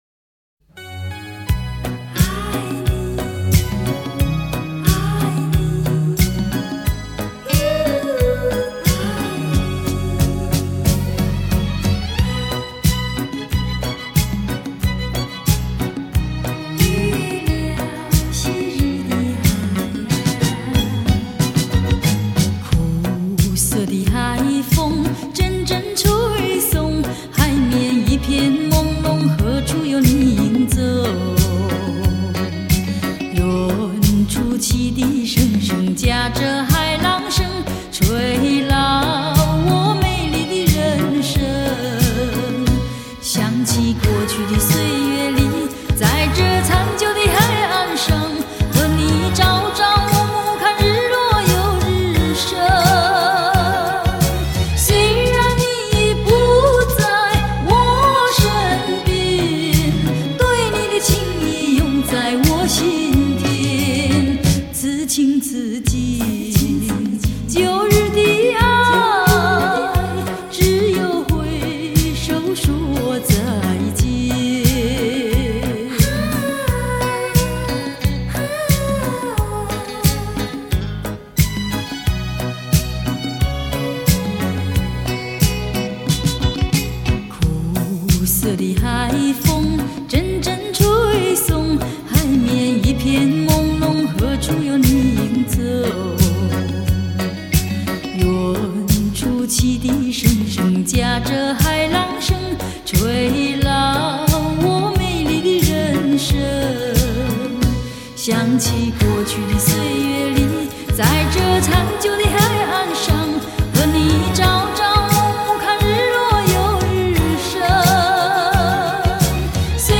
录音室：Oscar Studio S'pore